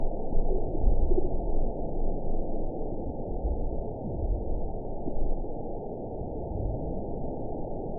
event 920477 date 03/27/24 time 03:35:13 GMT (1 month ago) score 7.58 location TSS-AB07 detected by nrw target species NRW annotations +NRW Spectrogram: Frequency (kHz) vs. Time (s) audio not available .wav